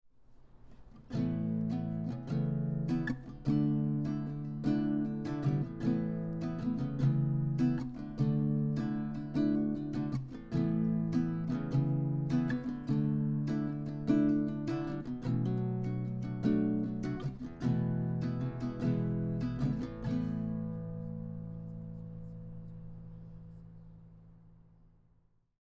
I ended up getting the Yamaha CGS102A, a half size nylon string acoustic guitar that has a clean, classic look with a great sound at a very reasonable price.
What I liked about the guitar is the full, warm and clean sound.
I’m using a Focusrite Scarlett audio interface to record these examples.
Chords 2 audio example
CGS102A-chords-2.mp3